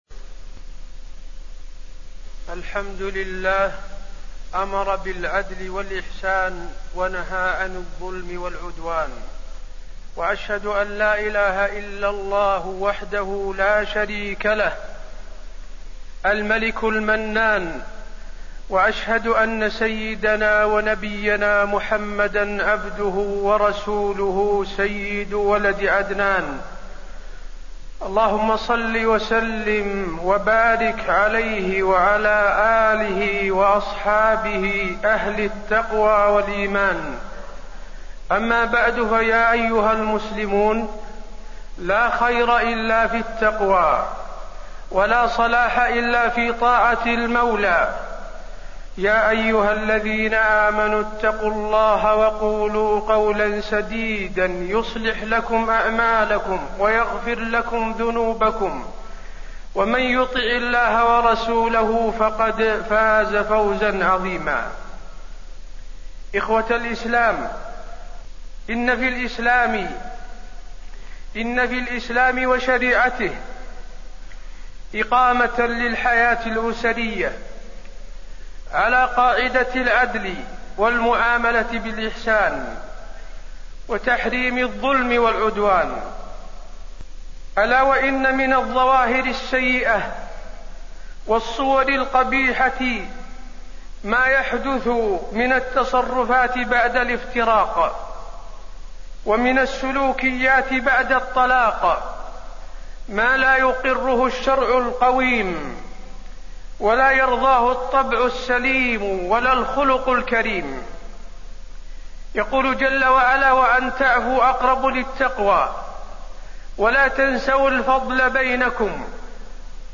تاريخ النشر ١٩ ربيع الأول ١٤٣١ هـ المكان: المسجد النبوي الشيخ: فضيلة الشيخ د. حسين بن عبدالعزيز آل الشيخ فضيلة الشيخ د. حسين بن عبدالعزيز آل الشيخ بعد الطلاق